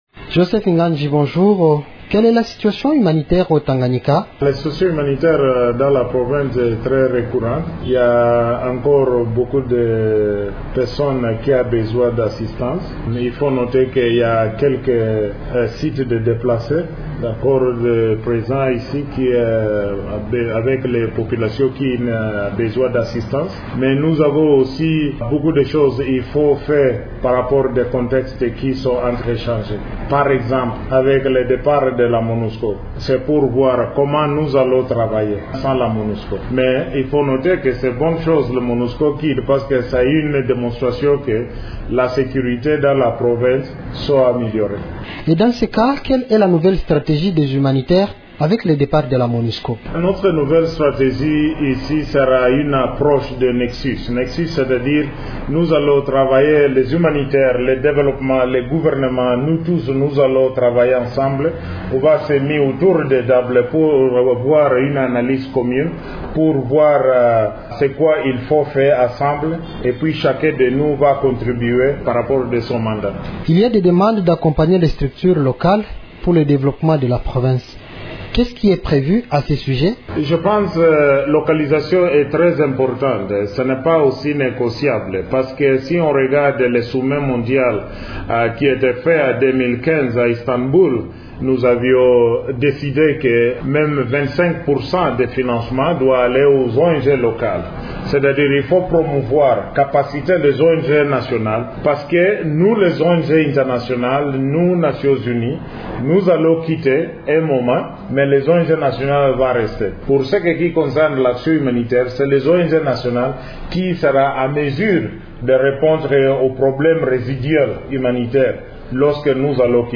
Il s'entretient avec